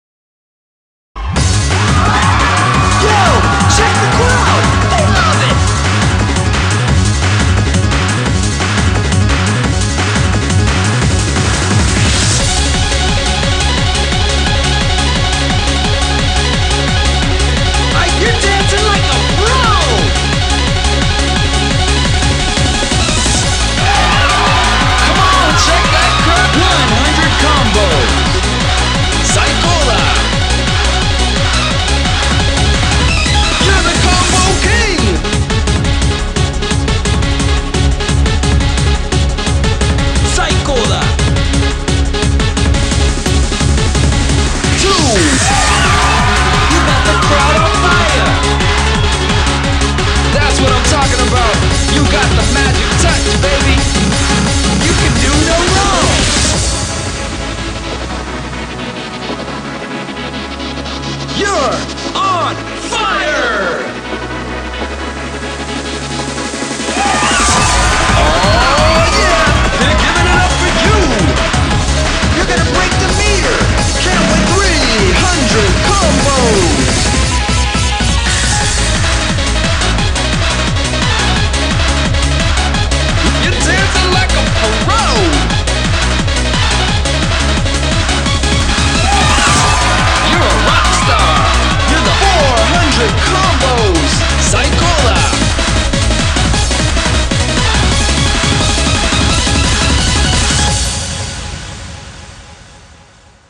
BPM174
Audio QualityLine Out